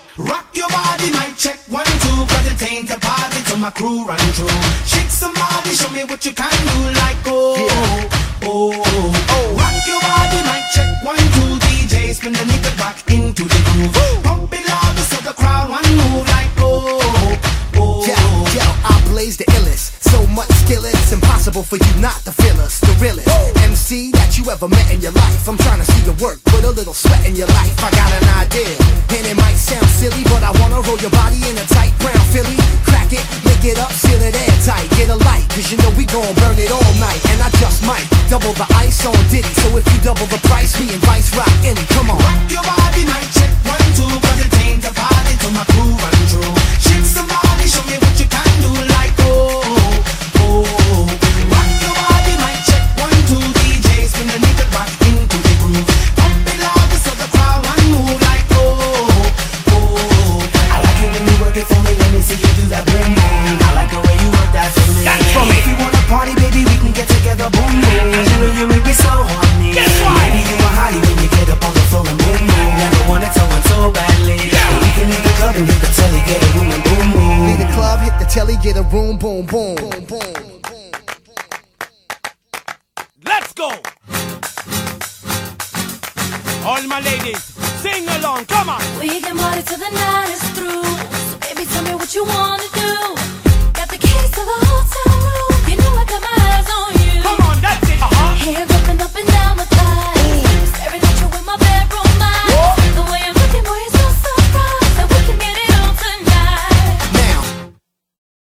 BPM103
Audio QualityMusic Cut
hip hop/pop group